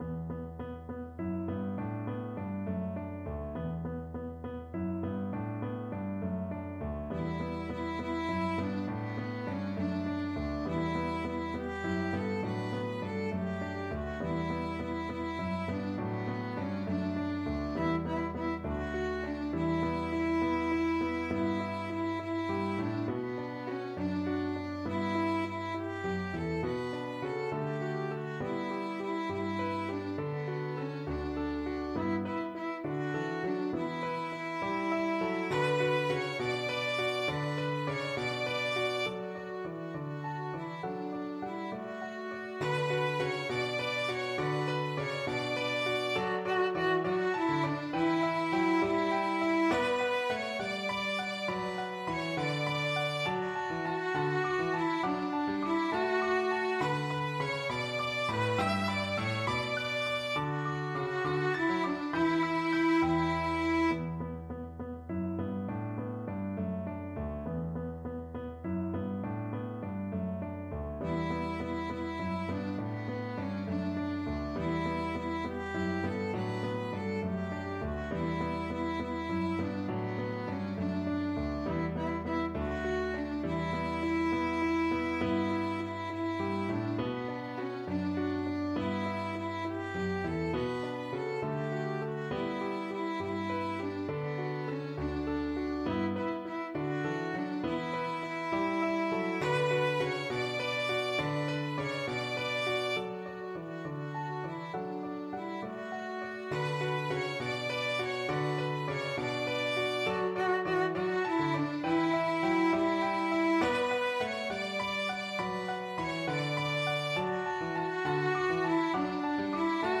Violin
Traditional Music of unknown author.
With energy . = c. 104
6/8 (View more 6/8 Music)
D major (Sounding Pitch) (View more D major Music for Violin )
Easy Level: Recommended for Beginners with some playing experience
Traditional (View more Traditional Violin Music)